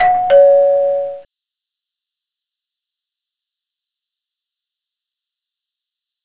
Du kannst ja die Lautstaerke einstellen und den Klingelton aendern. Ich hab ein wesentlich dezenteres "ding-dong" mit langer Stille dahinter verwendet:
tuerklingel_aussen.wav